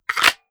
Kydex Holster 002.wav